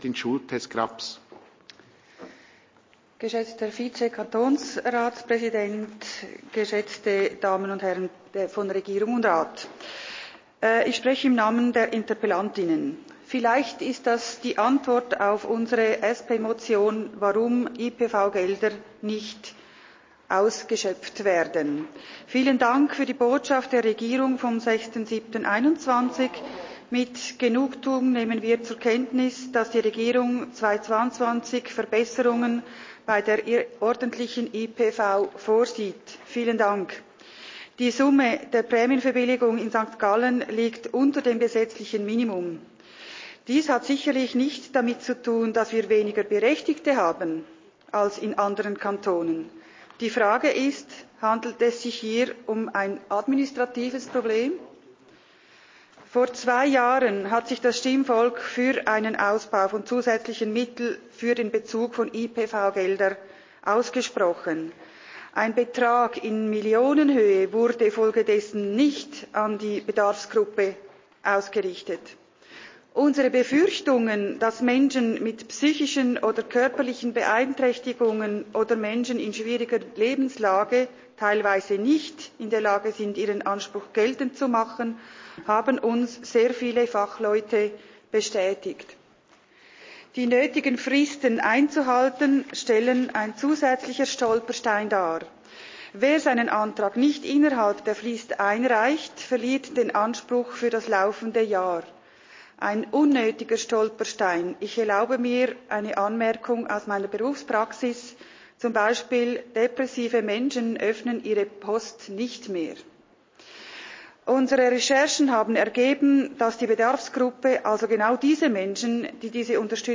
Session des Kantonsrates vom 20. bis 22. September 2021